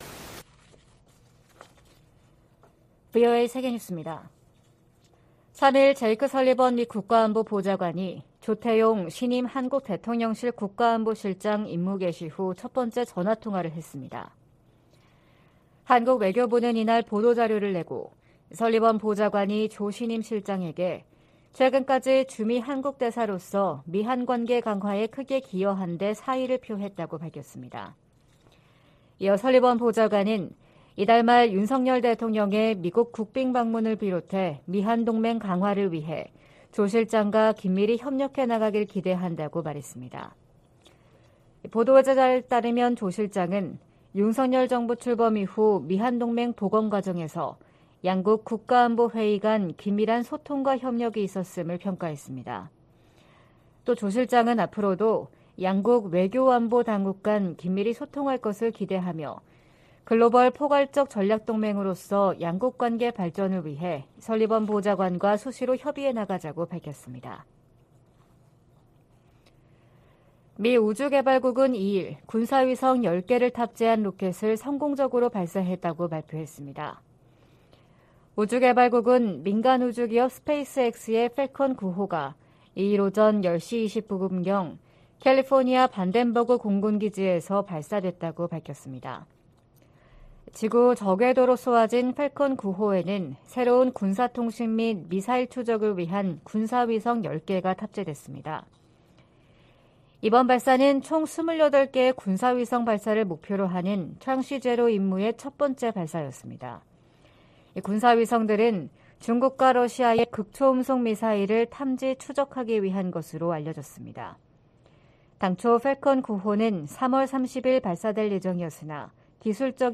VOA 한국어 '출발 뉴스 쇼', 2023년 4월 4일 방송입니다. 미국 상원은 최근 대통령 무력사용권 공식 폐지 법안을 가결했습니다.